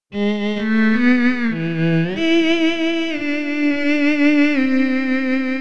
violonchel.wav